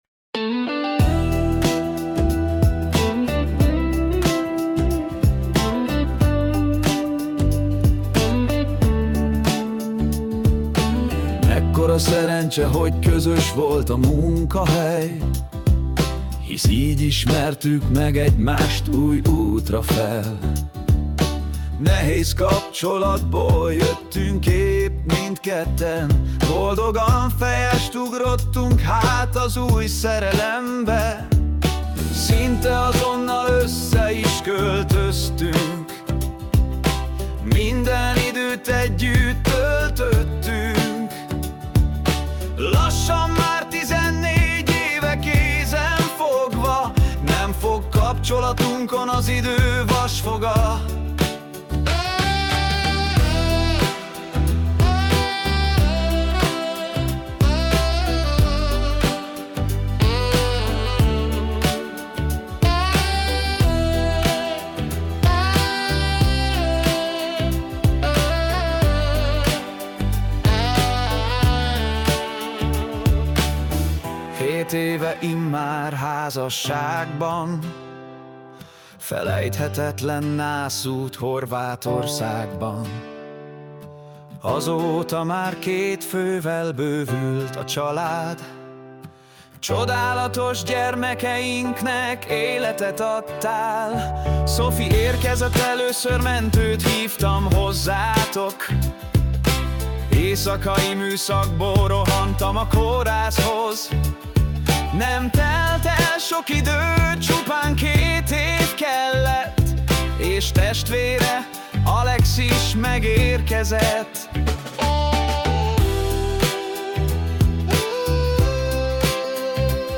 Pop - Szülinapra
Személyre szabott ajándék dal - Alkalom: szülinapra